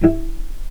vc_pz-E4-pp.AIF